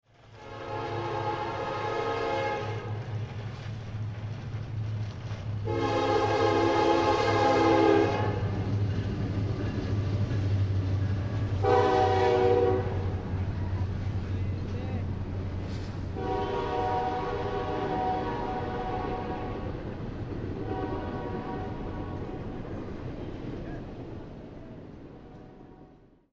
Звуки тепловоза
Звук гудка локомотива